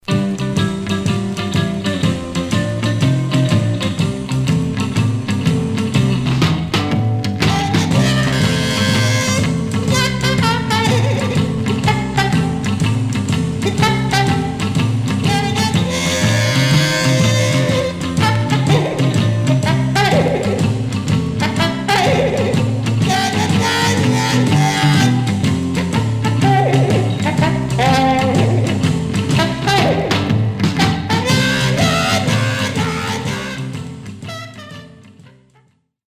Stereo/mono Mono
R & R Instrumental Condition